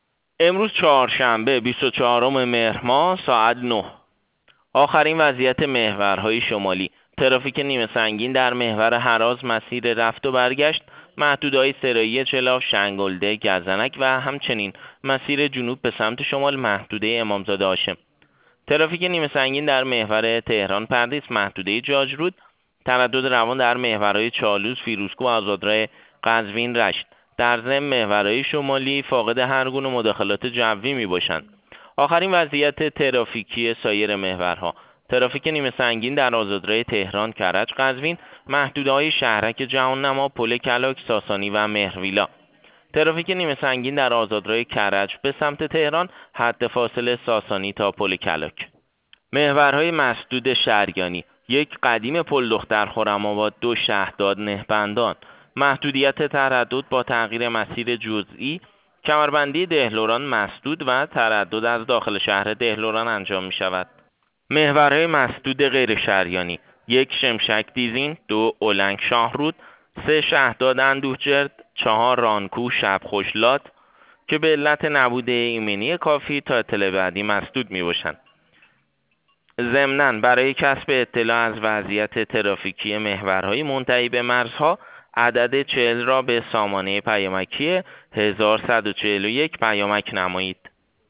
گزارش رادیو اینترنتی پایگاه خبری وزارت راه و شهرسازی از آخرین وضعیت ترافیکی جاده‌های کشور تا ساعت ۹ چهارشنبه ۲۳ مهر